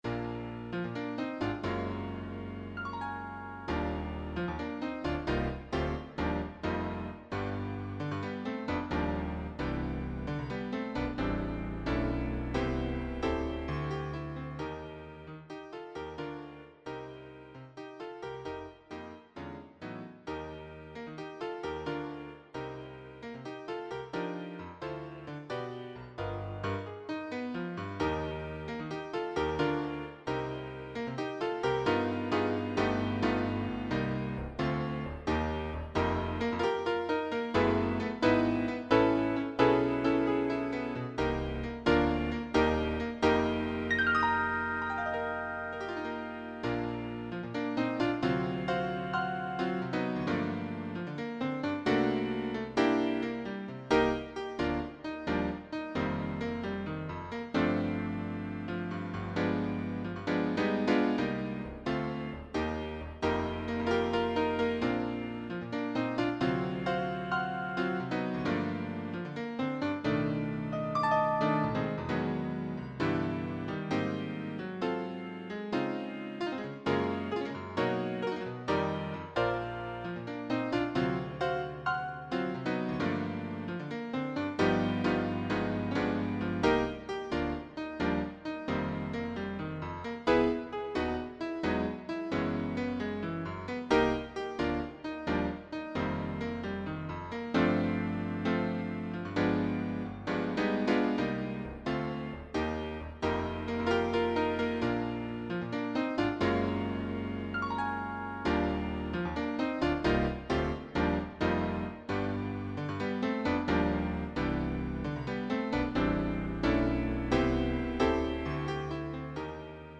jazz piano/ensemble
Electronically Generated